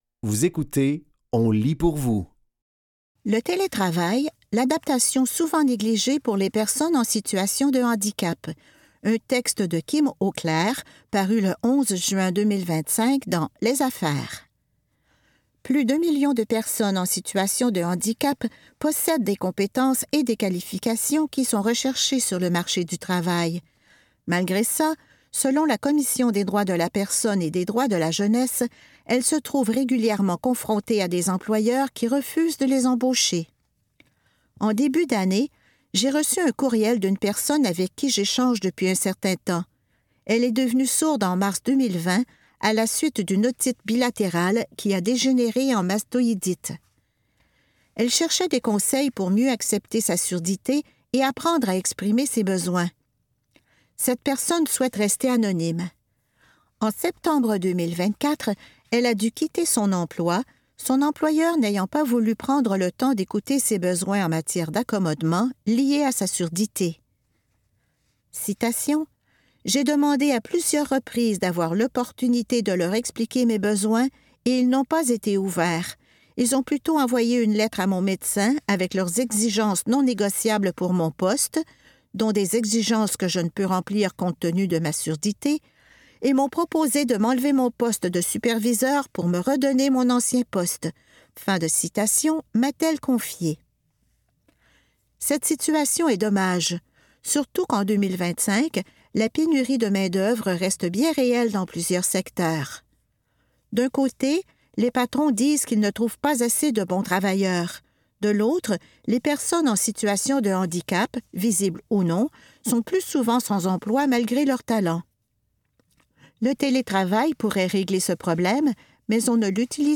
Dans cet épisode de On lit pour vous, nous vous offrons une sélection de textes tirés des médias suivants : Les Affaires, Radio-Canada et La Conversation.